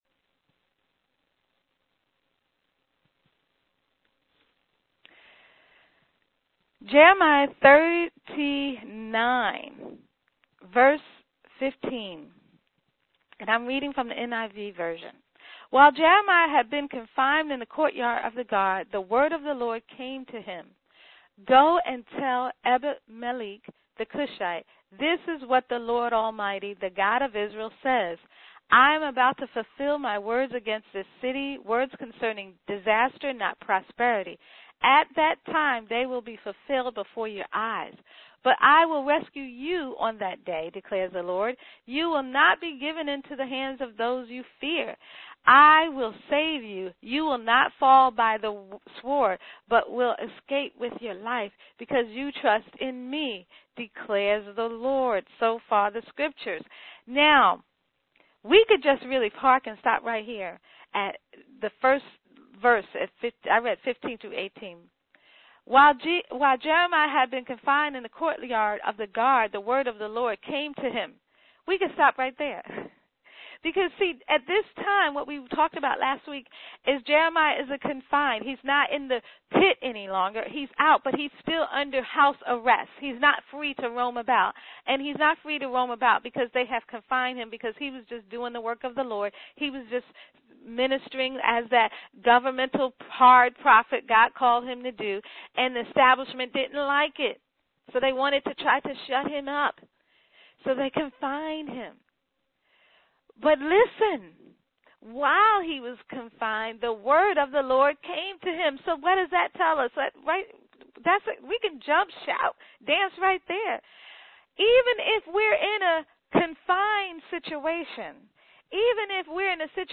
Listen to this 10 min devotional AUDIO TEACHING